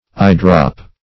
Eyedrop \Eye"drop"\, n.